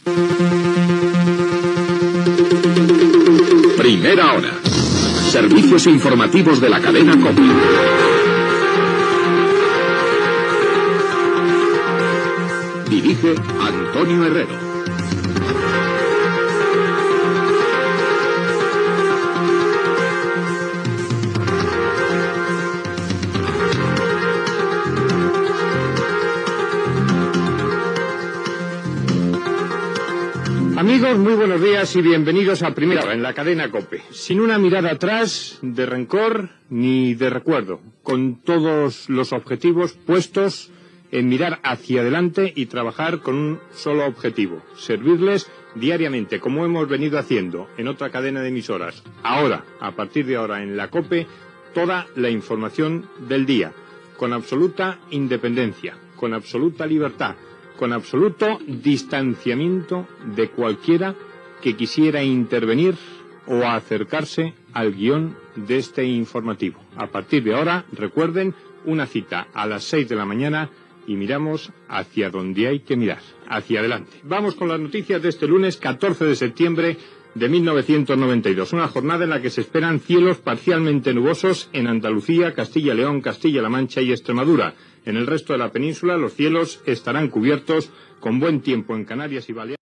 Careta del programa, presentació del primer programa, data, estat del temps
Informatiu